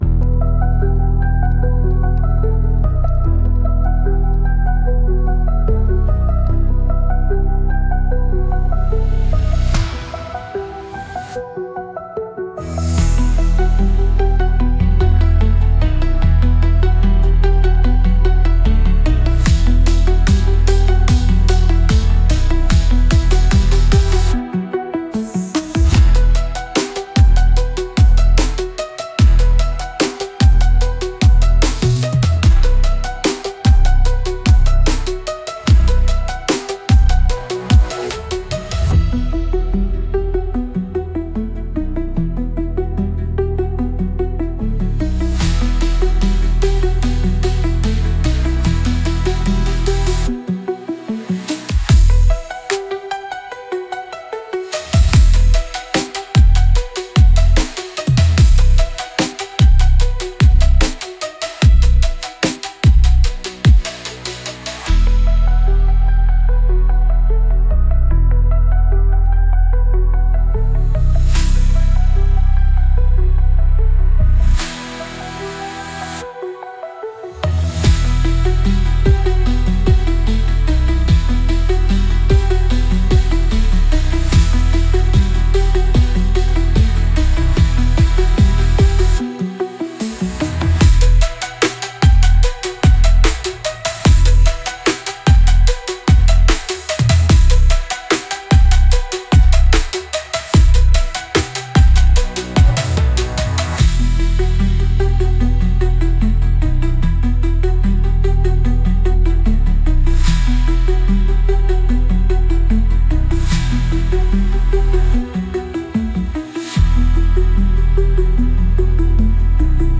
Instrumental - Cosmic Crawl